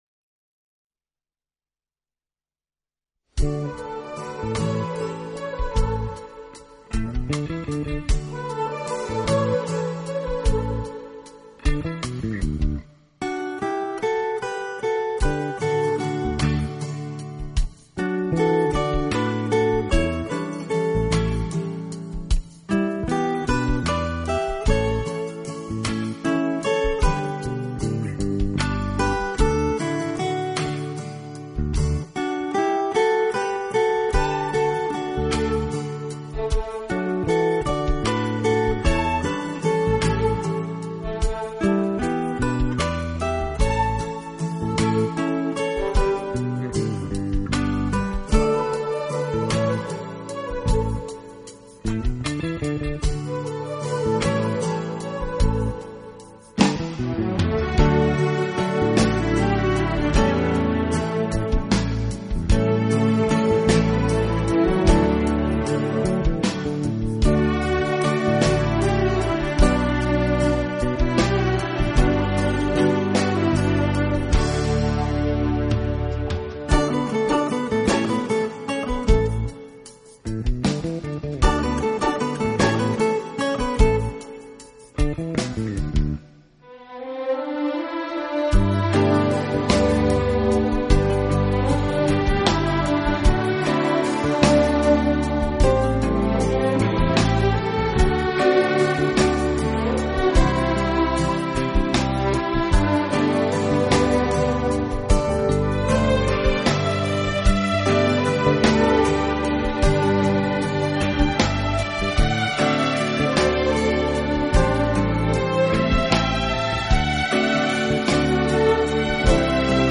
好处的管乐组合，给人以美不胜收之感。